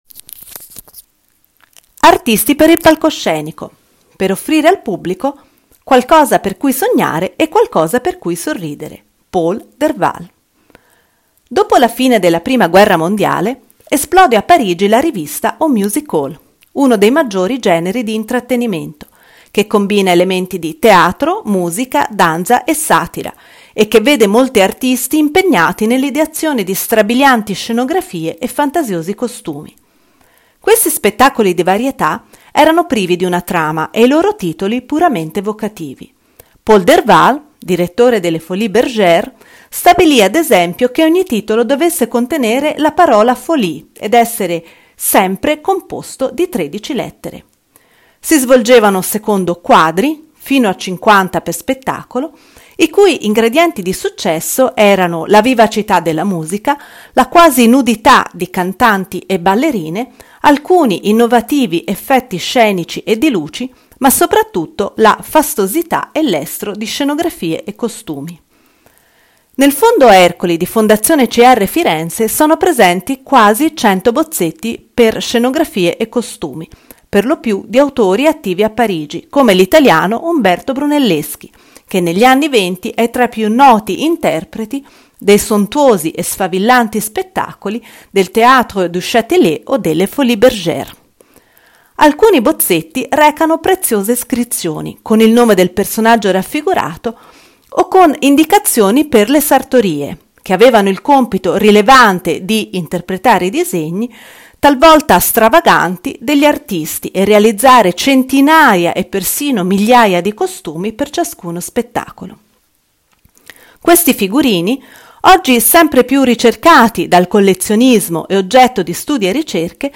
AUDIOGUIDA MOSTRA